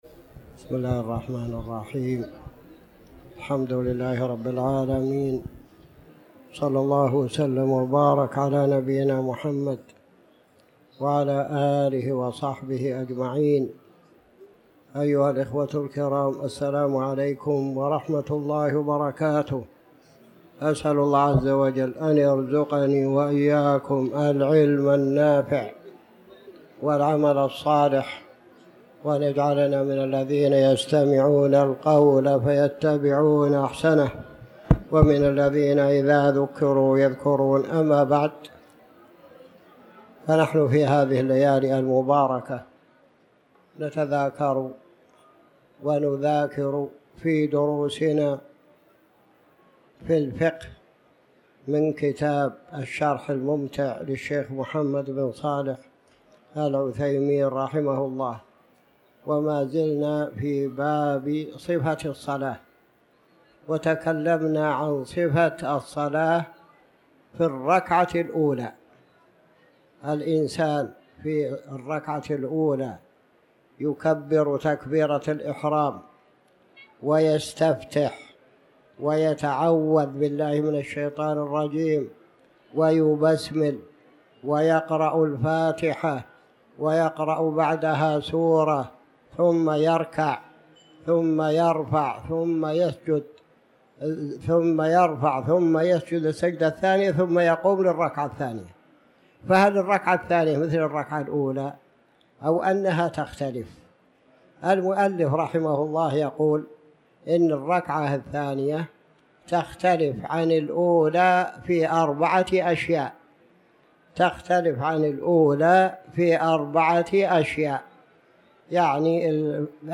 تاريخ النشر ١٢ ذو القعدة ١٤٤٠ هـ المكان: المسجد الحرام الشيخ